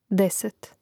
dȅset deset